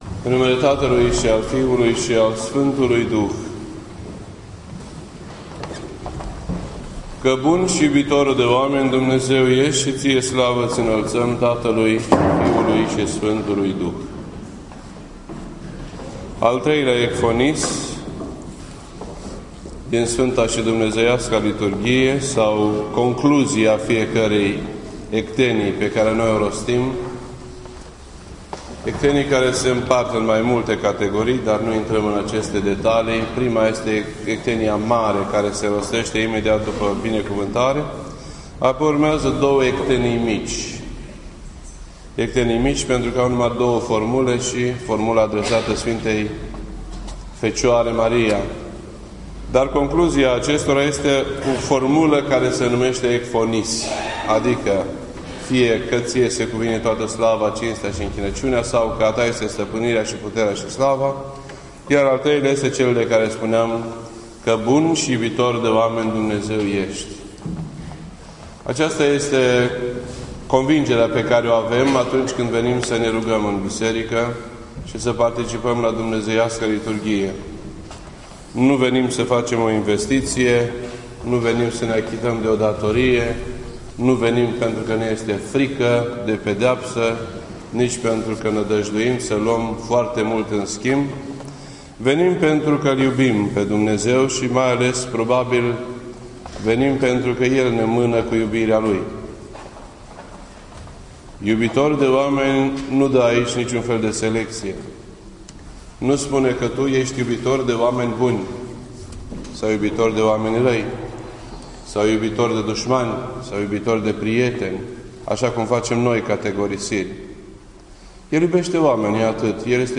This entry was posted on Sunday, October 5th, 2014 at 12:18 PM and is filed under Predici ortodoxe in format audio.